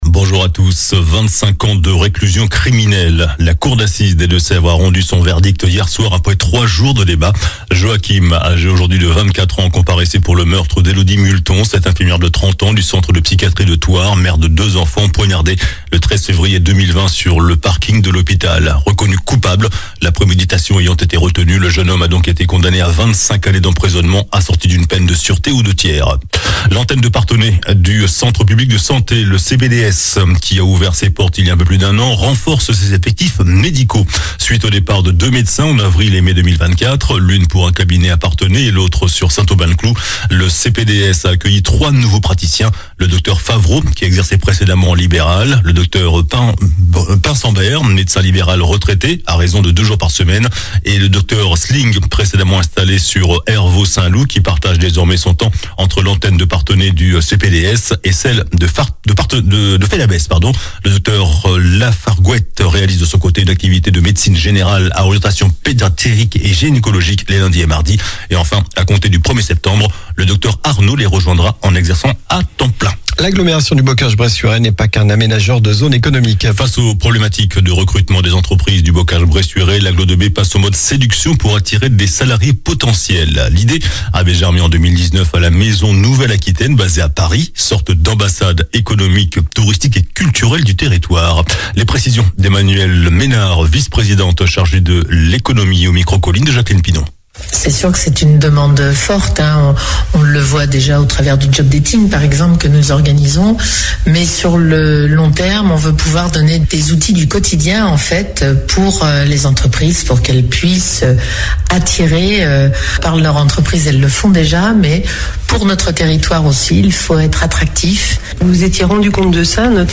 JOURNAL DU SAMEDI 06 JUILLET